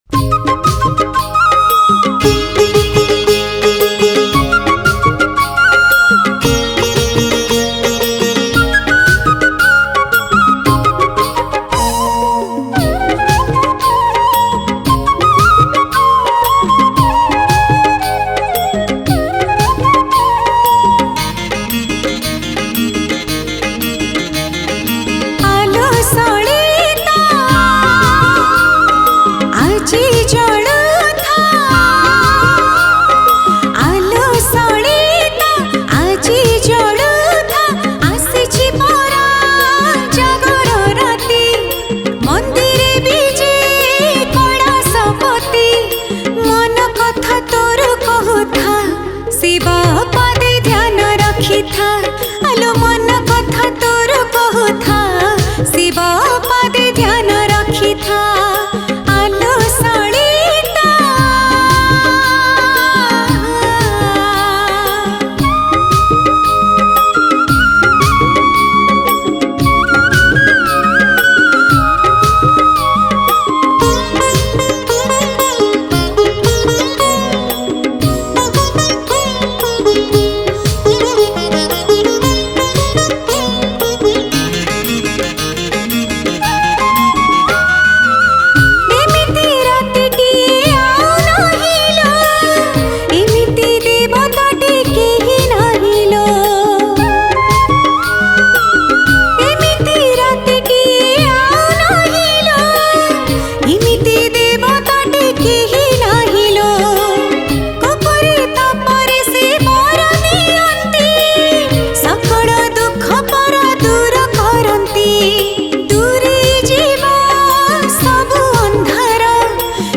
Jagara Special Odia Bhajan Song 2022